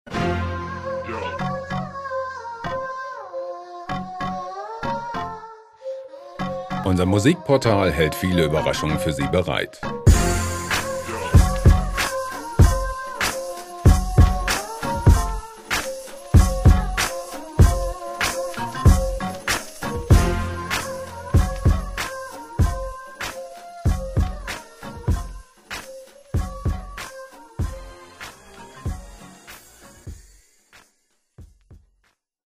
gema-freie Hip-Hop Loops
Musikstil: Bhangra Hop
Tempo: 96 bpm